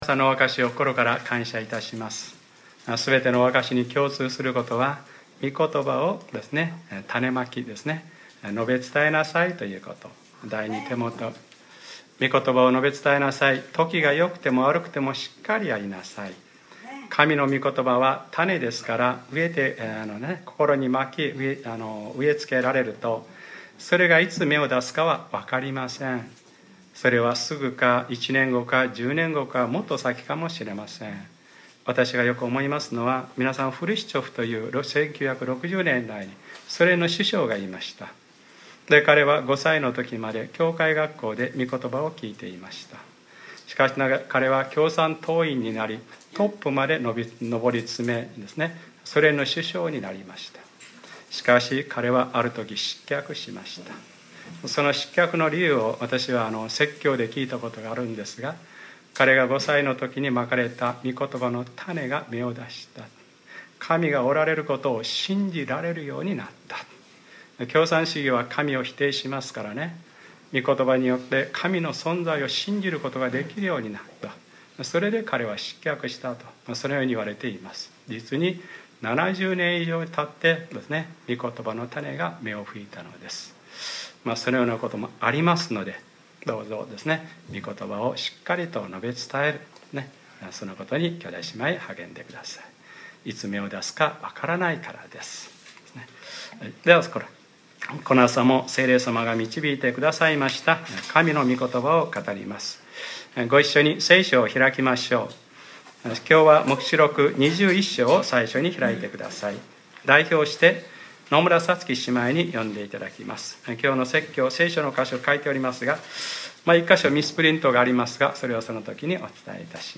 2014年9月28日（日）礼拝説教 『黙示録ｰ３４：見よ。神の幕屋が人とともにある』